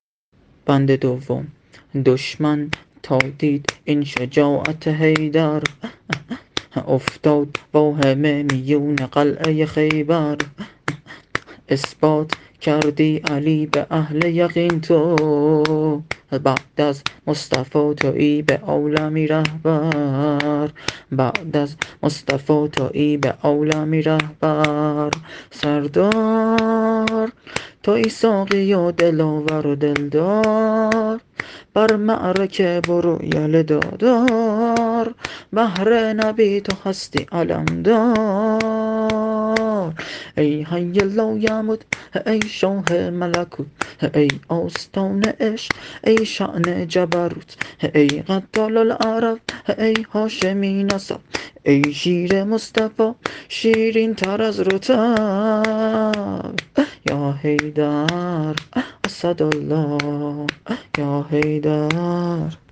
شور حضرت علی (ع) -(احمد رو کرد به حیدر برو میدون)